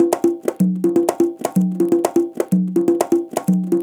Live Percussion A 07.wav